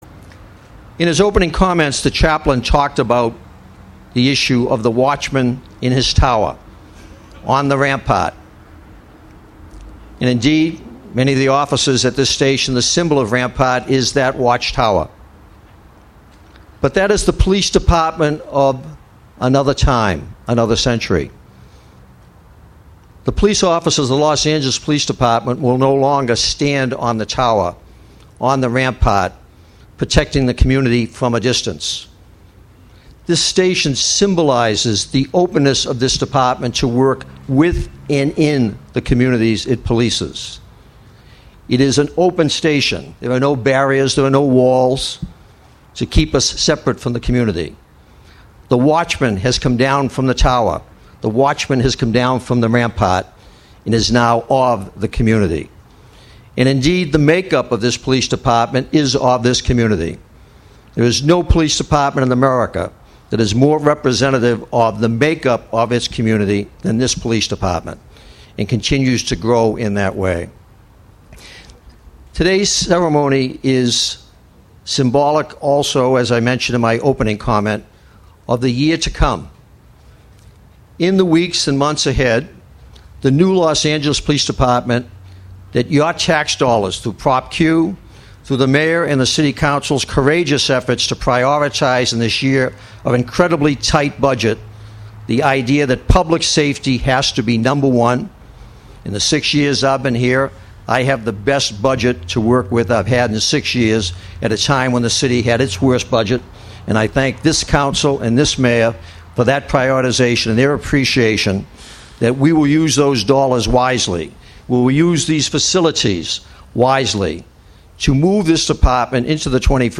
The new and improved Rampart Community Police Station was unveiled this morning to a crowd of dignitaries, neighborhood leaders and community members.